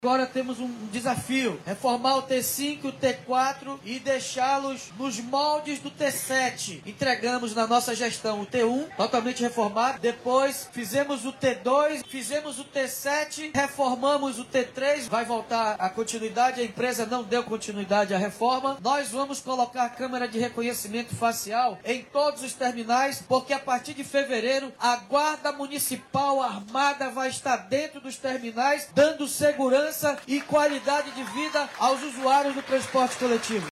Durante a entrega de novos ônibus, na manhã desta sexta-feira 03/01, o Prefeito de Manaus, Davi Almeida, anunciou o aumento da tarifa do Transporte Coletivo, para este ano.